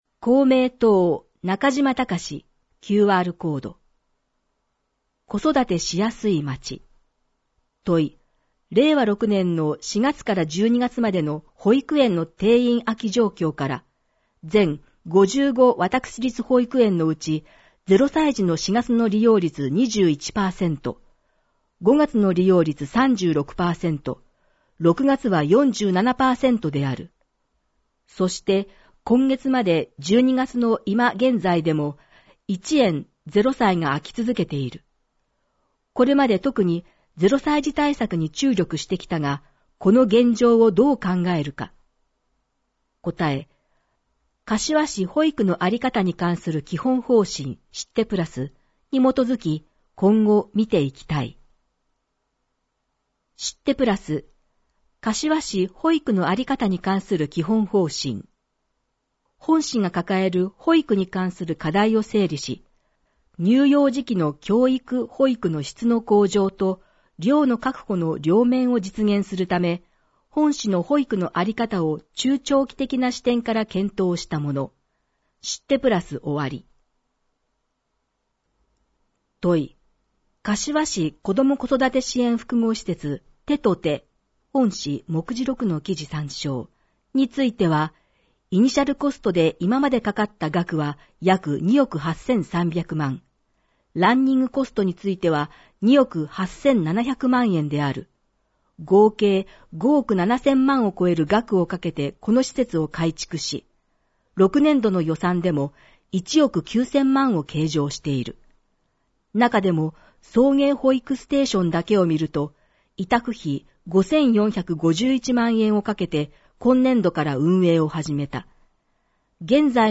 • かしわ市議会だよりの内容を音声で収録した「かしわ市議会だより音訳版」を発行しています。
• 発行は、柏市朗読奉仕サークル（外部サイトへリンク）にご協力いただき、毎号行っています。